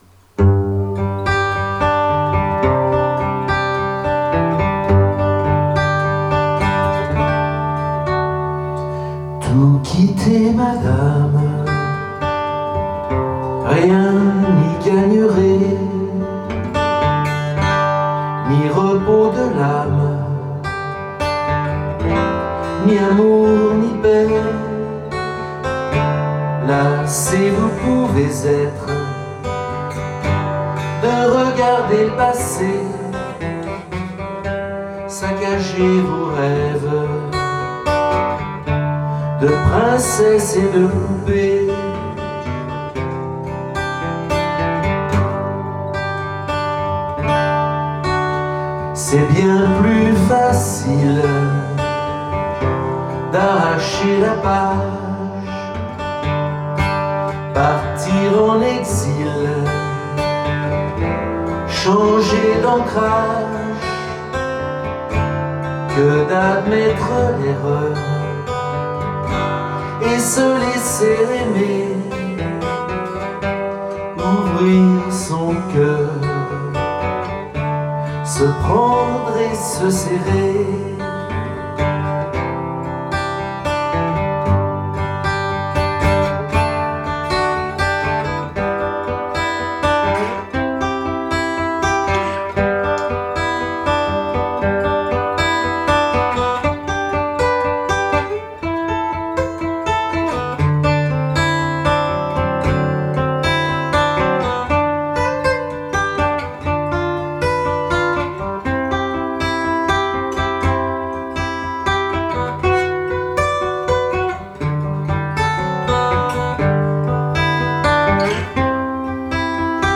Slow en boîte de nuit :